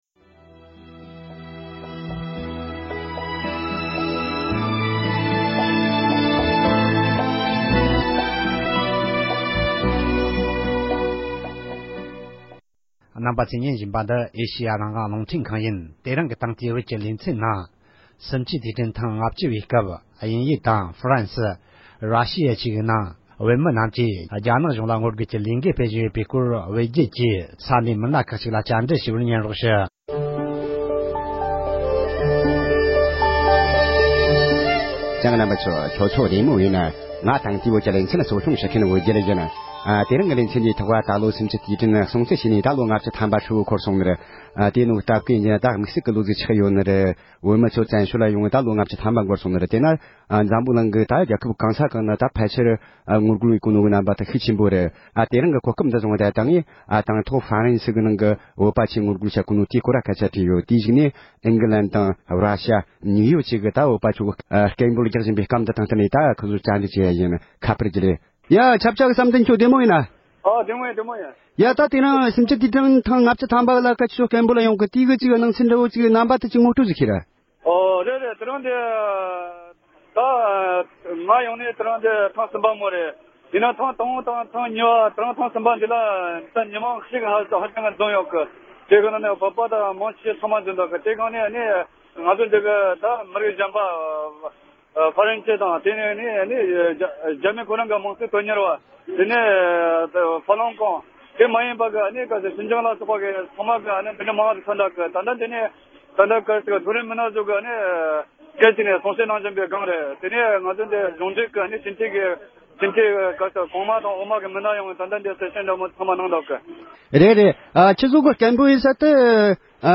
༄༅༎དེ་རིང་གི་དེང་དུས་བོད་ཅེས་པའི་ལེ་ཚན་ནང་དུ་སུམ་བཅུའི་དུས་དྲན་ཐེངས་ལྔ་བཅུ་པའི་སྐབས་དང་བསྟུན་ནས་ཕ་རན་སི་དང་དབྱིན་ཇི་དེ་བཞིན་ར་ཞིཡ་བཅས་པའི་ནང་བོད་མི་ཚོས་རྒྱ་གཞུང་ལ་ངོ་རྒོལ་སྐད་འབོད་བྱེད་བཞིན་པའི་སྐོར་ས་གནས་ཀྱི་བོད་མི་ཁག་ཅིག་ལ་བོད་རྒྱལ་གྱིས་ཁ་པར་བརྒྱུད་ནས་གནས་འདྲི་ཞུས་པ་ཞིག་གསན་རོགས་གནང་༎